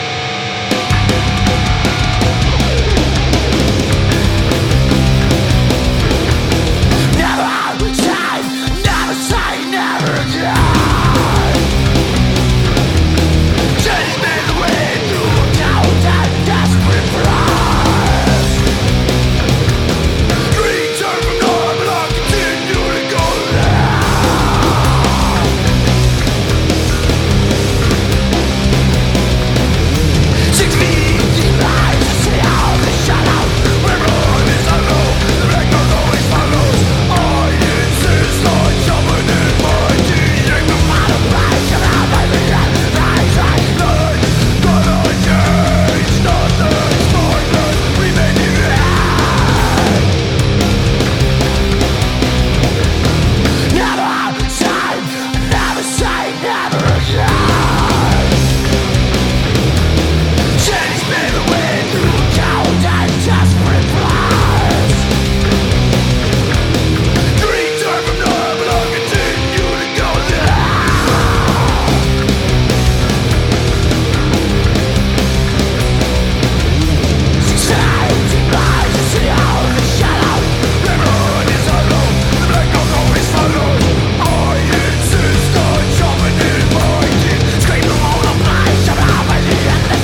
ALT./EXPERIMENTAL / POST ROCK